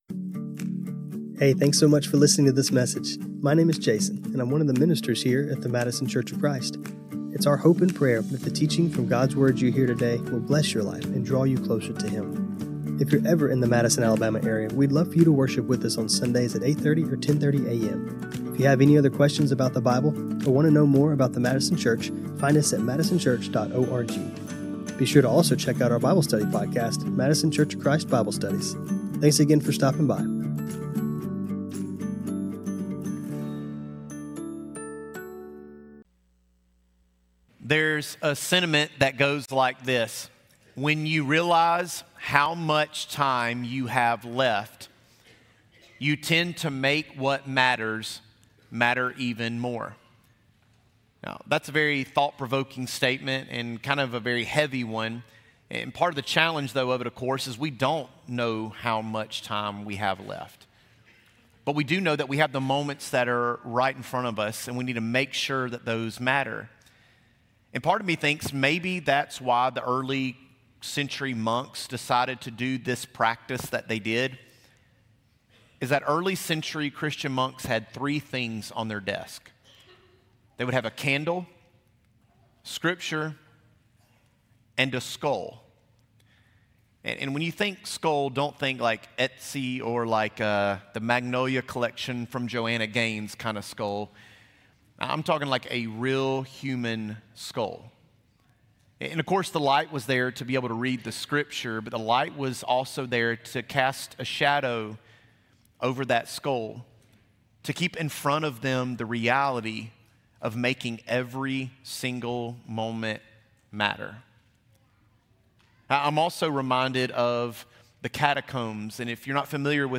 This sermon was recorded on Mar 1, 2026.